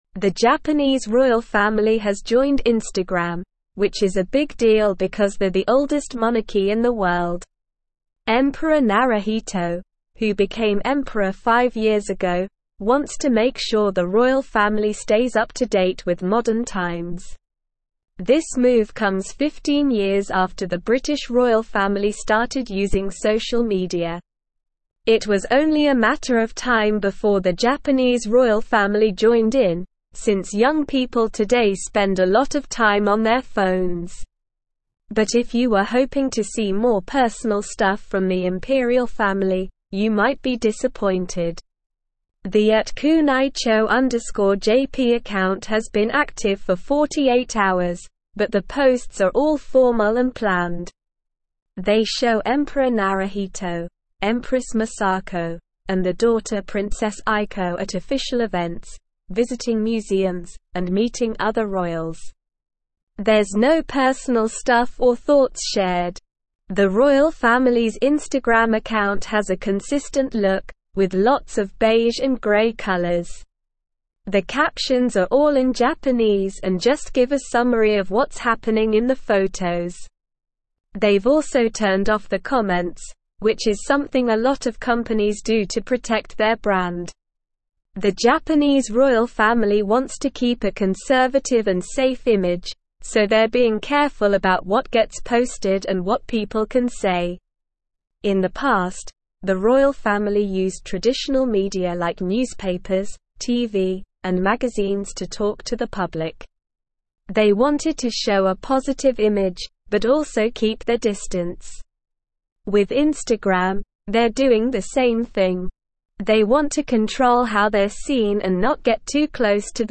Slow
English-Newsroom-Upper-Intermediate-SLOW-Reading-Japanese-Imperial-Family-Joins-Instagram-to-Modernize.mp3